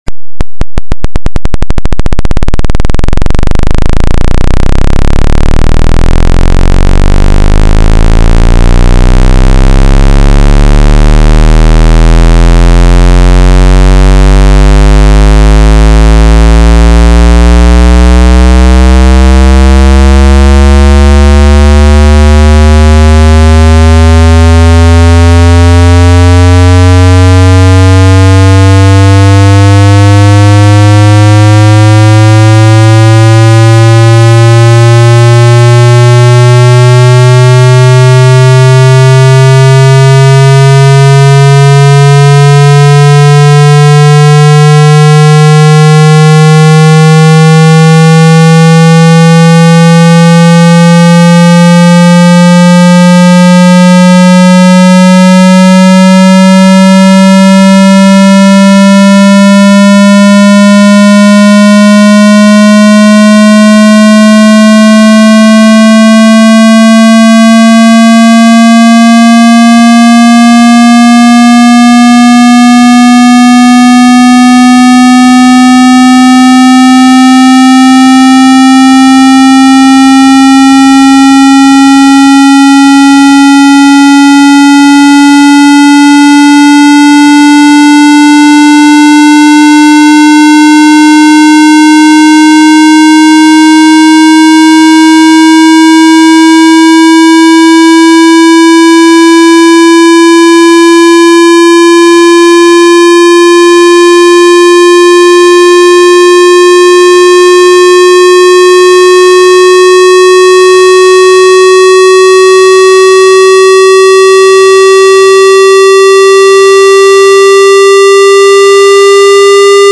1Hz 1000Hz Squared Super Slow Sweep sound effects free download
1Hz-1000Hz Squared Super Slow Sweep Mp3 Sound Effect